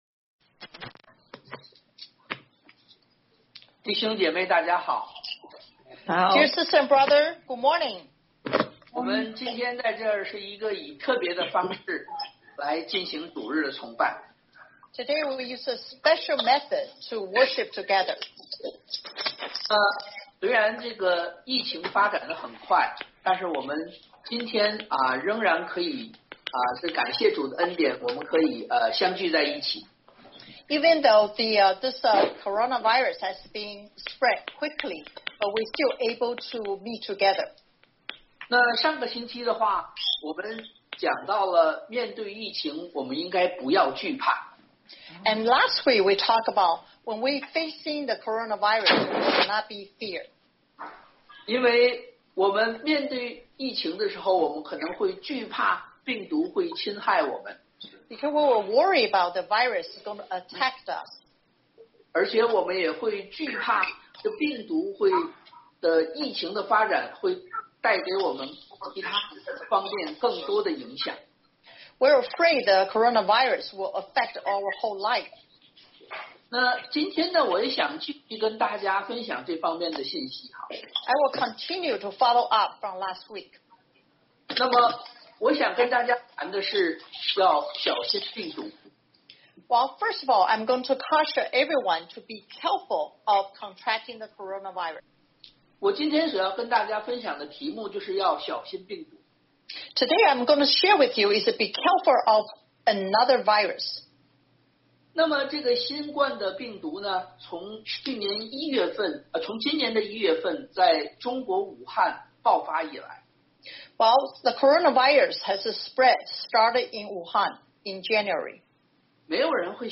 Service Type: Sunday AM
First web-based worship recording 教會首次网上崇拜视频 video click here 点击观看视频 « 2020-03-15 Fear Not 2020-03-29 Did God Send the Coronavirus?